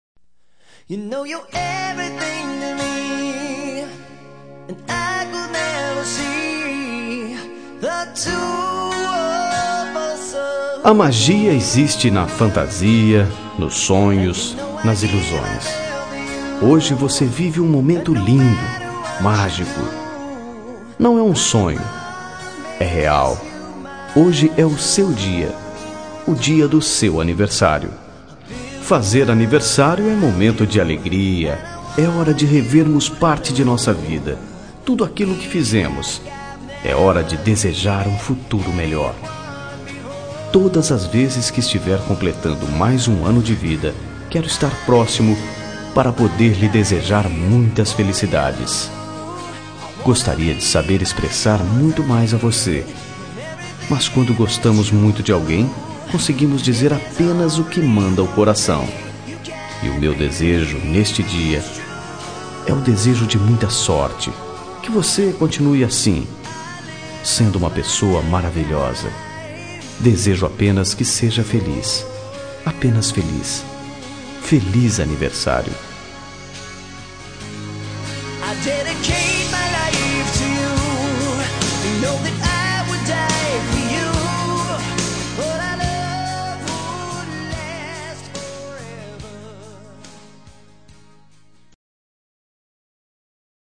Telemensagem Aniversário de Amiga – Voz Masculina – Cód: 1588